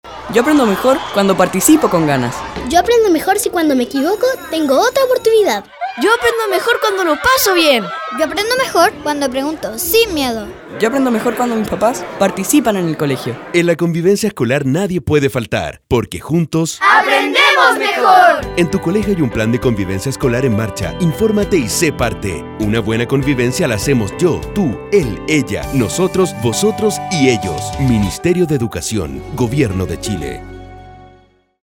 Audio donde niños expresan que aprenden mejor cuando hay una buena convivencia escolar y el Misterio de Educación invita a informarse y participar del plan de convivencia escolar del colegio.